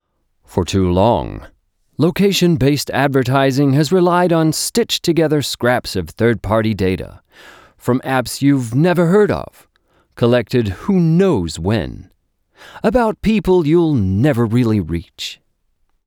Hi, if you need a soothing calm or fun voice, lets go
Middle Aged
I have a professional studio setup with a sound booth.
Life place VO (US).wav